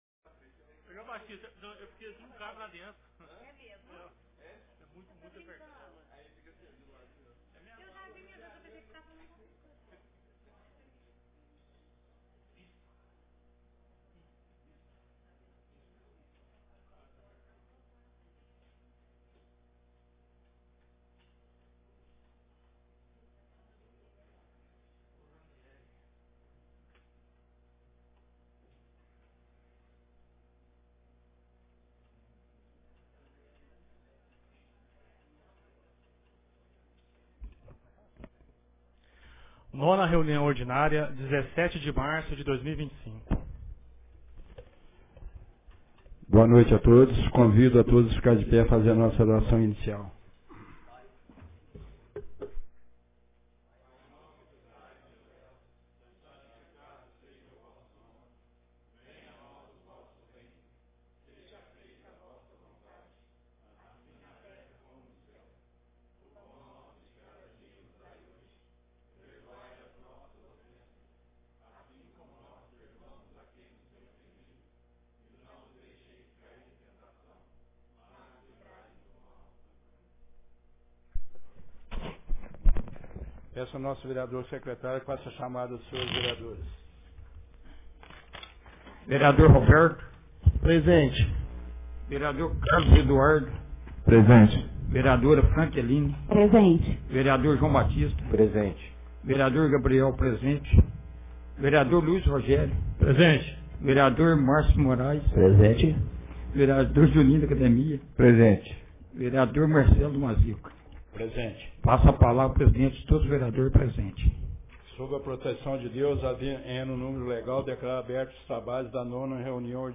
Ata da 9ª Reunião Ordinária de 2025